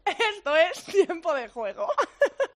Las risas de Tiempo de Juego
Los sonidos del maquinillo